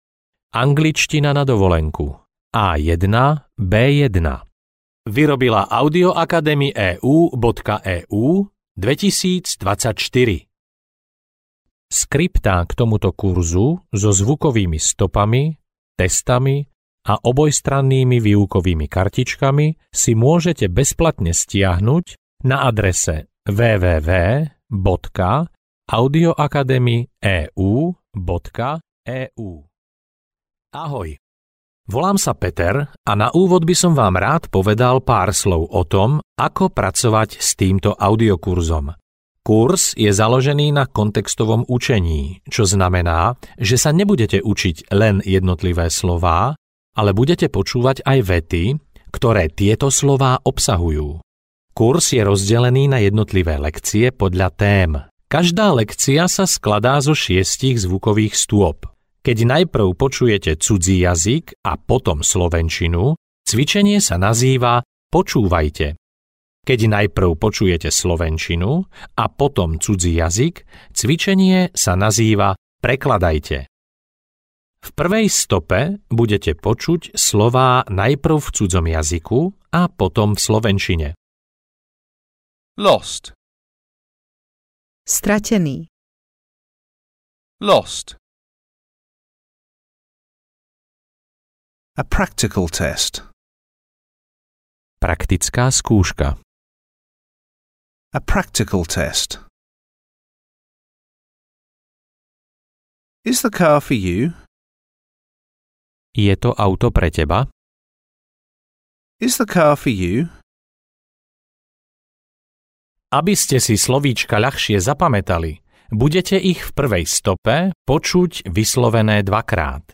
Audio knihaAngličtina na dovolenku A1-B1
Ukázka z knihy